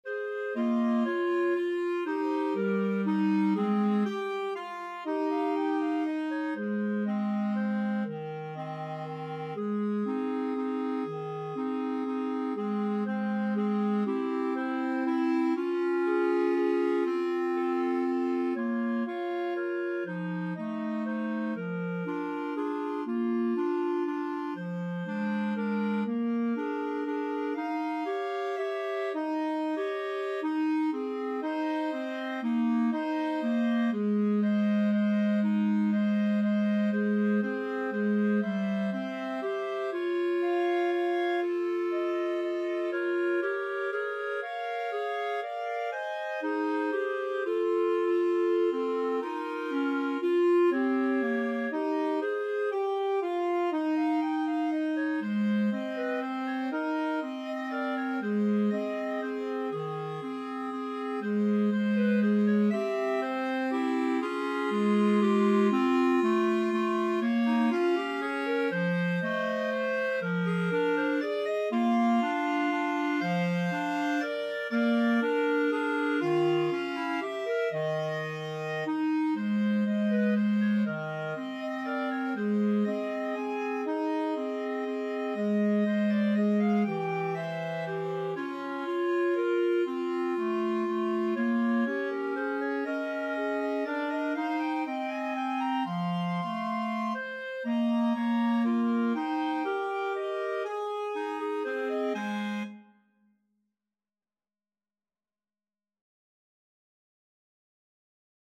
3/4 (View more 3/4 Music)
= 120 Tempo di Valse = c. 120
Jazz (View more Jazz Clarinet Trio Music)
Rock and pop (View more Rock and pop Clarinet Trio Music)